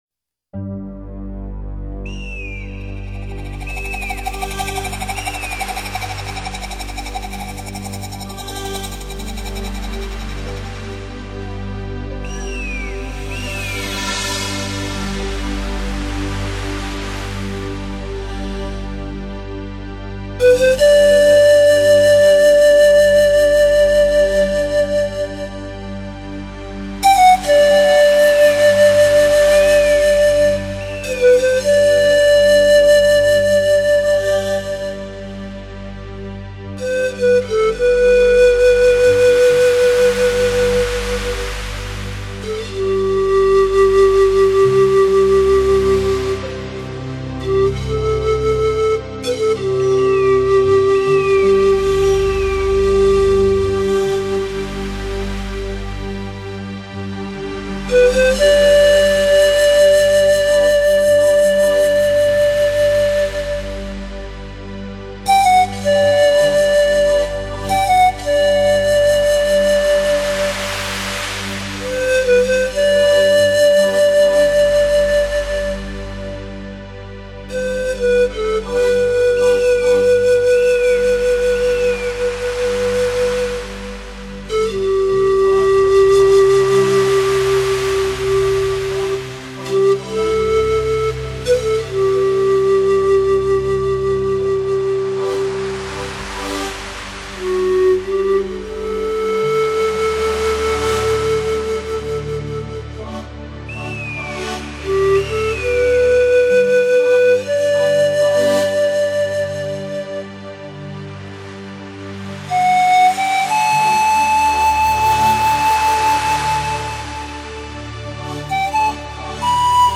所属：NewAge
南美土著 音乐具有轻快的节奏与不能自己的热情, 在排笛、吉他各式各样 的打击乐器的衬托下，感受大排萧的气息声